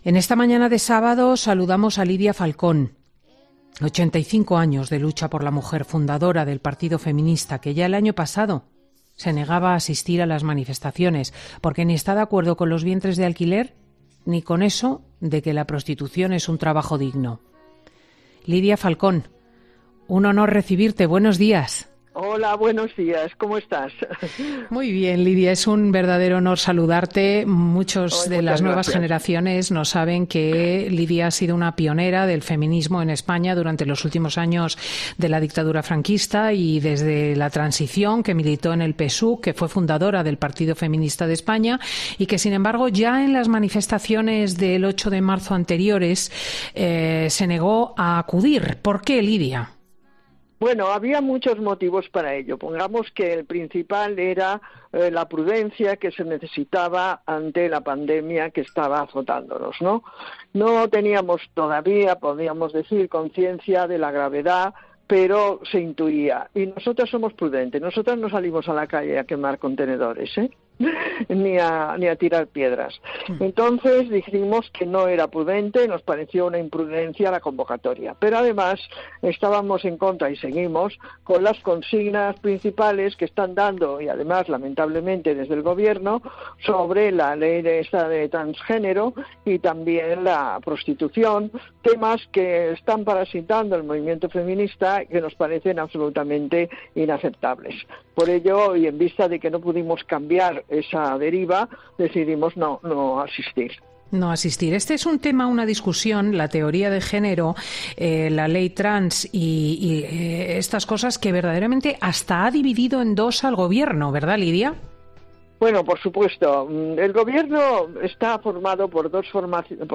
La histórica feminista pasa por los micrófonos de Fin de Semana para reafirmar sus motivos para no acudir a las manifestaciones del 8-M